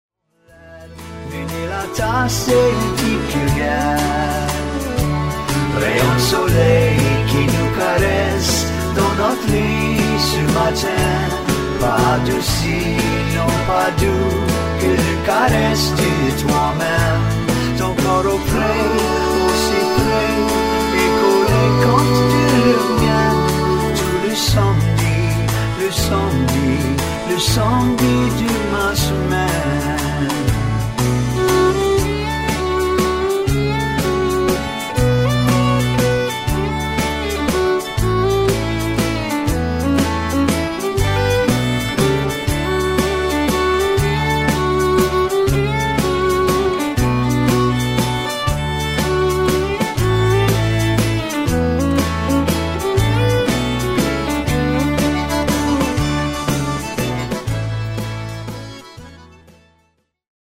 Accordion, Vocals and Harmony Vocals, Bass Guitar
Fiddle on 2
Drums on all